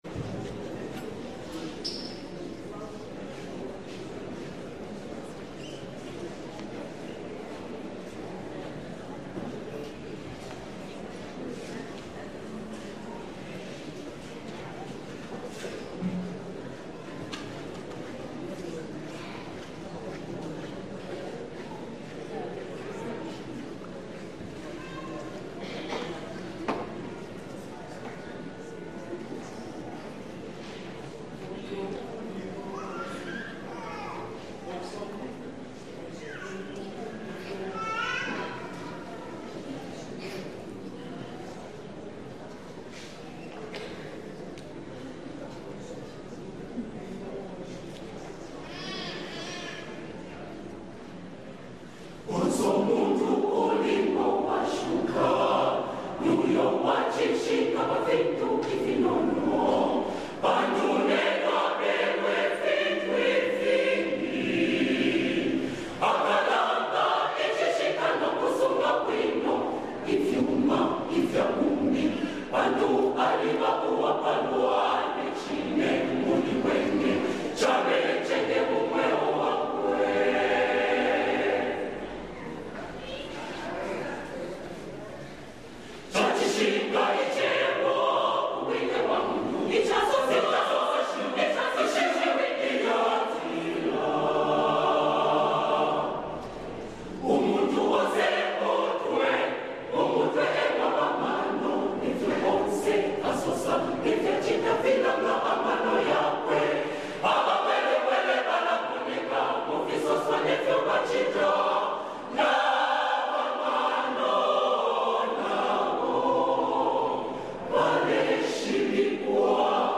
POWERFUL CHORAL PRAISE | 2025 ZAMBIA GOSPEL